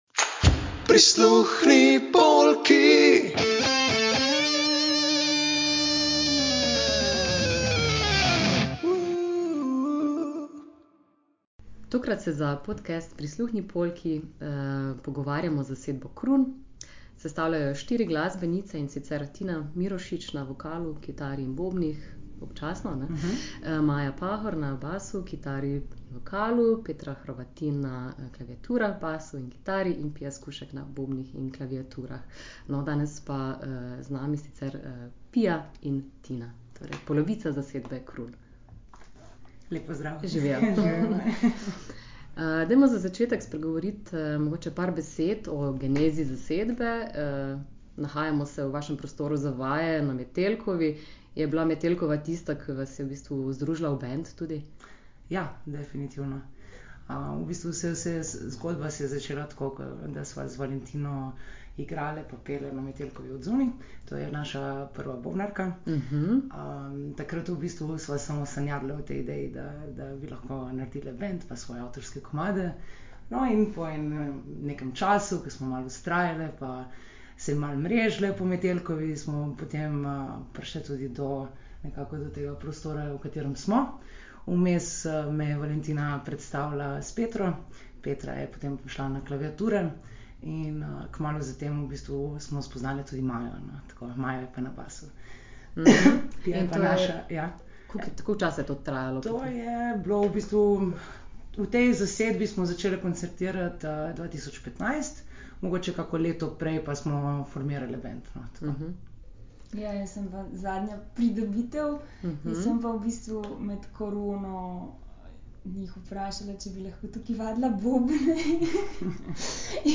Tokrat smo v studiu za vaje obiskali štiričlansko žensko zasedbo Croon.
Intervju-Croon-final.mp3